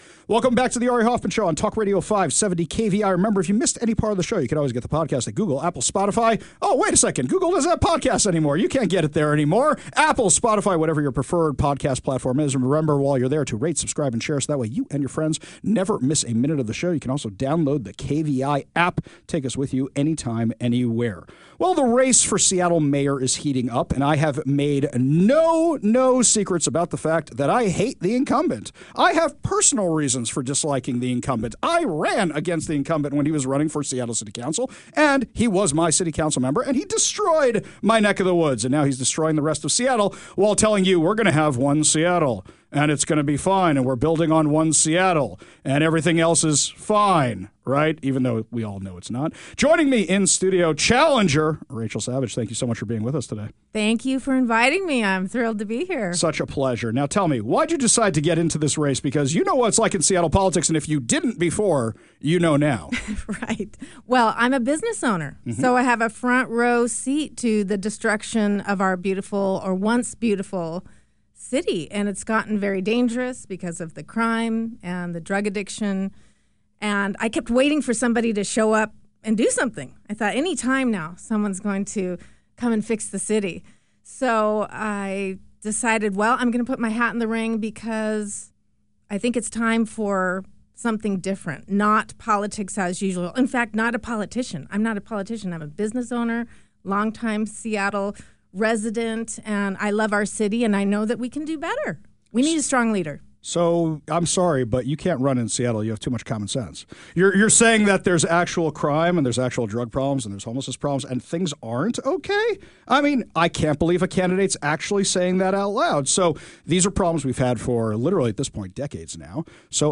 in-studio